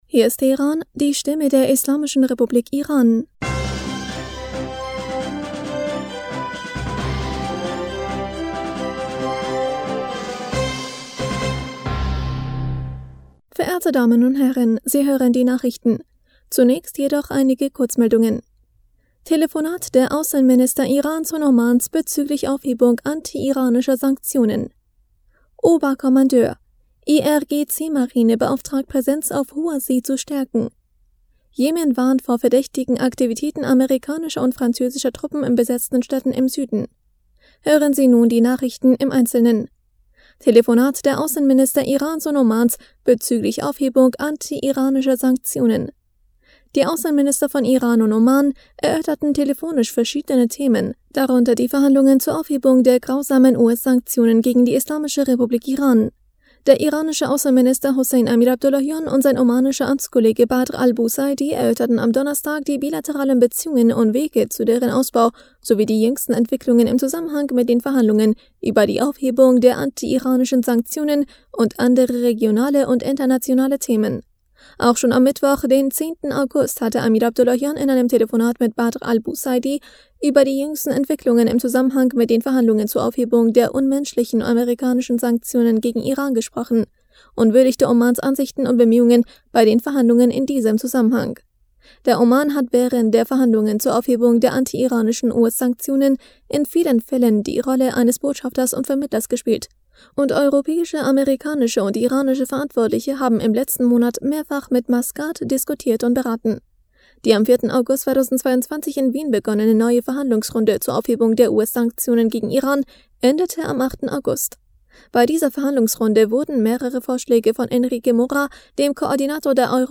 Nachrichten vom 19. August 2022